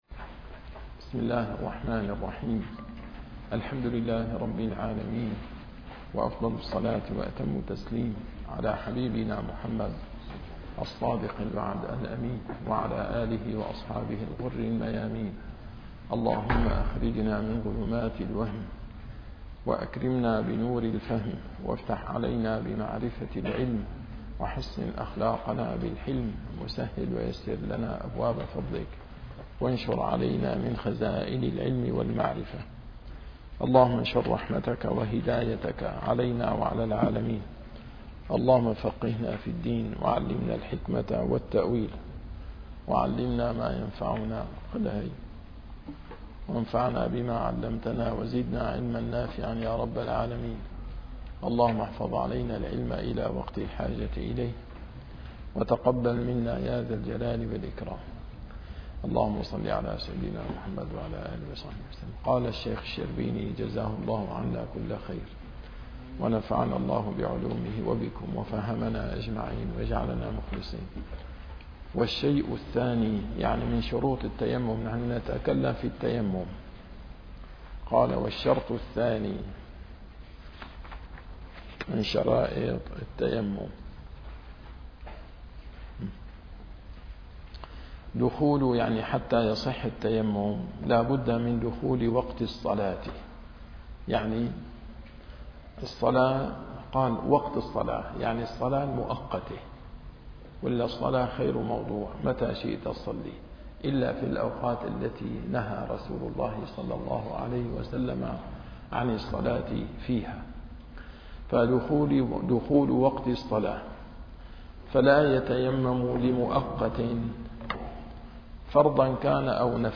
- الدروس العلمية - الفقه الشافعي - كتاب الإقناع - شروط التيمم من ص 67 الى ص 68